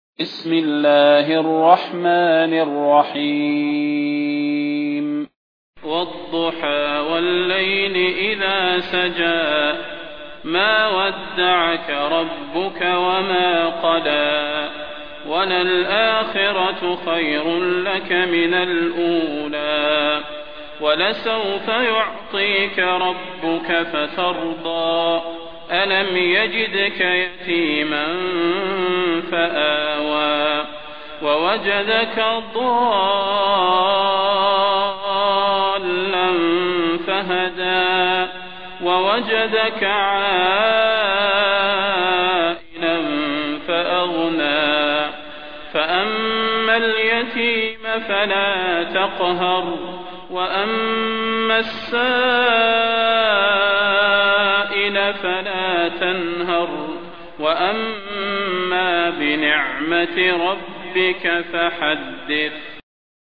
المكان: المسجد النبوي الشيخ: فضيلة الشيخ د. صلاح بن محمد البدير فضيلة الشيخ د. صلاح بن محمد البدير الضحى